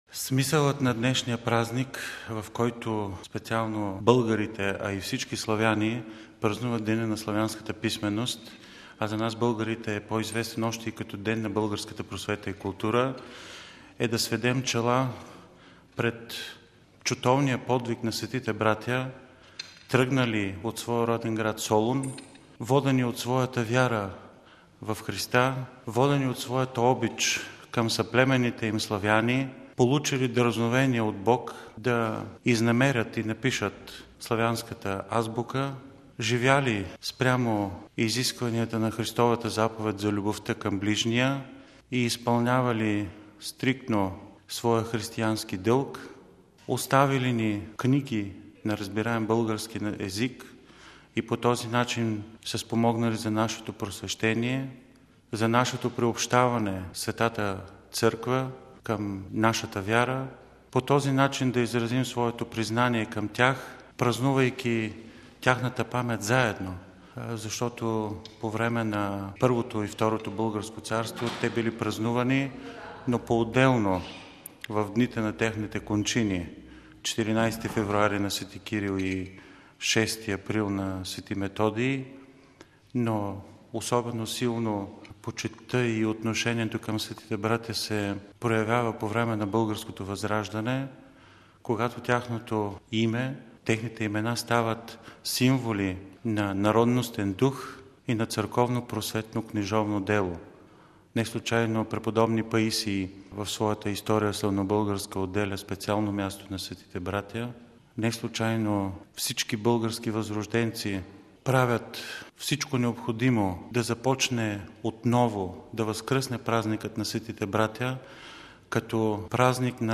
За дълбокият смисъл на днешния празник говори пред микрофона на Радио Ватикана епископ Наум: RealAudio